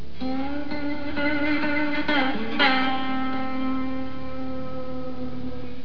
Intro Music - 129264 bytes